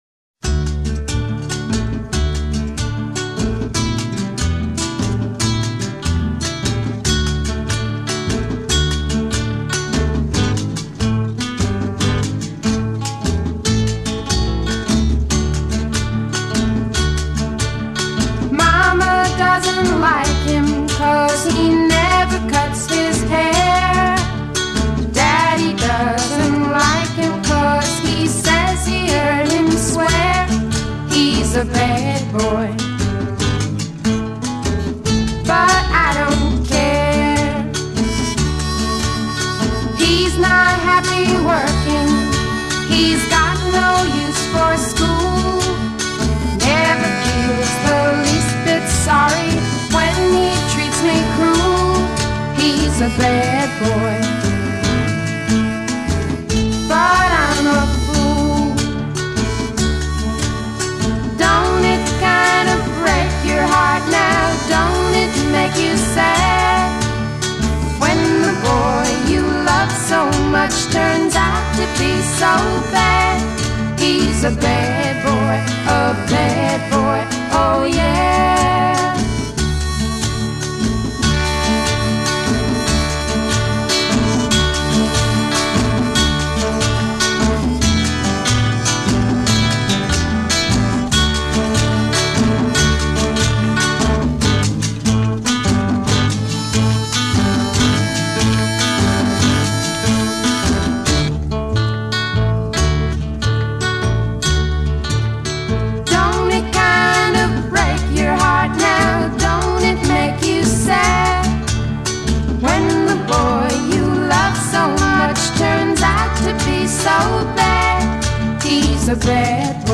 the wheezing, asthmatic harmonica solo
reimagined as a plaintive girl group heartbreaker!